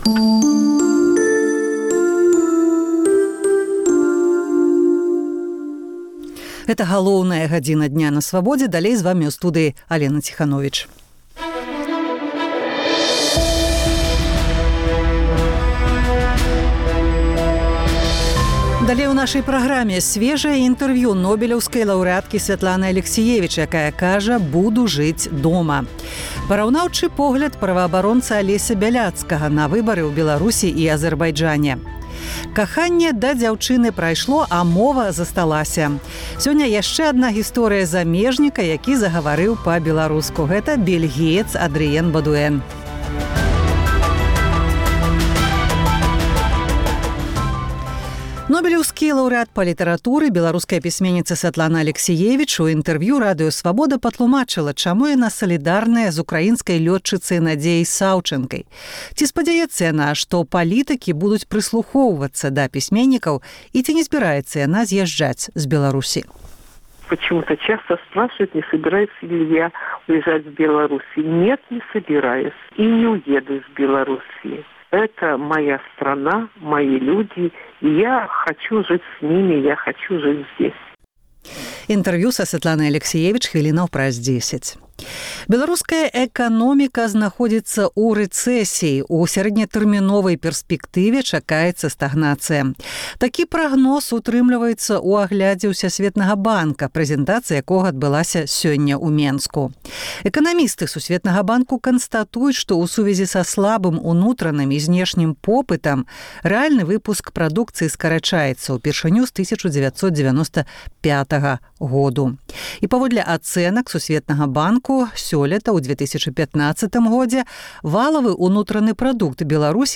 Сьвежае інтэрвію Нобэлеўскай ляўрэаткі Сьвятланы Алексіевіч. Параўнаўчы погляд праваабаронцы Алеся Бяляцкага на выбары ў Беларусі і Азэрбайджане.